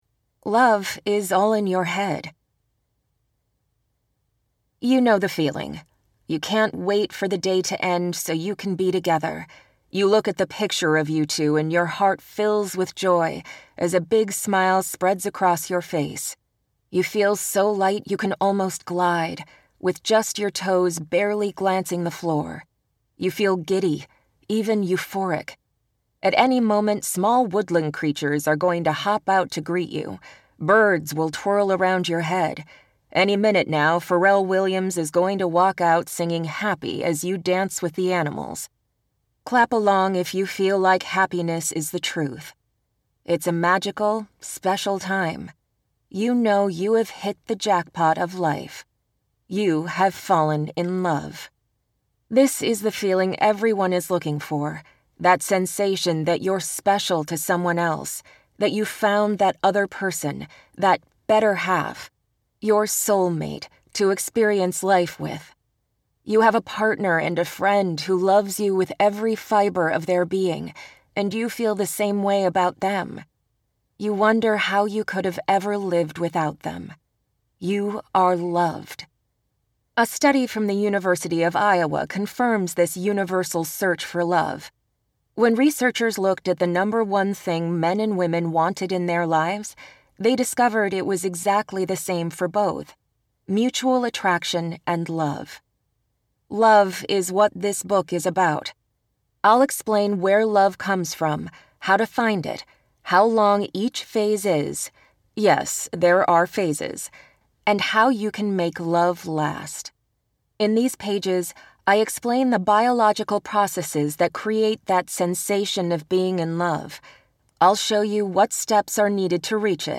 Men Chase, Women Choose - Vibrance Press Audiobooks - Vibrance Press Audiobooks